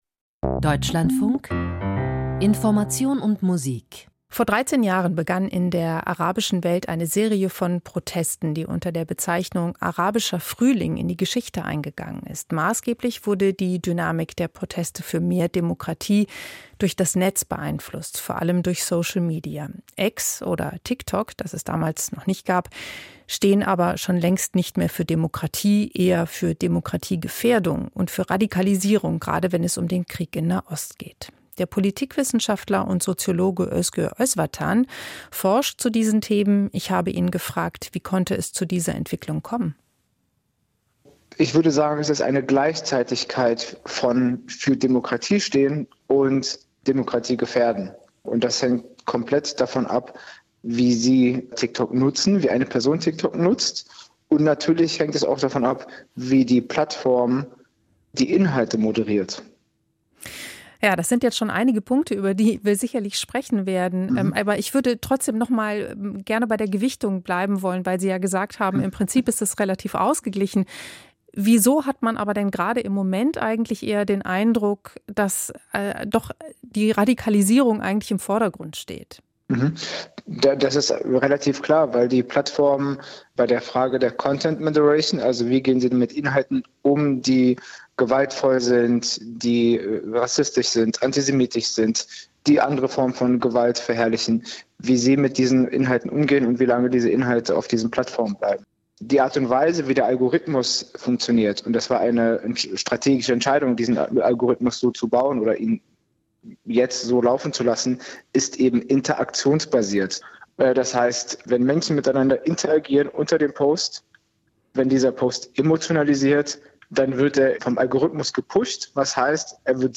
Politikwissenschaftler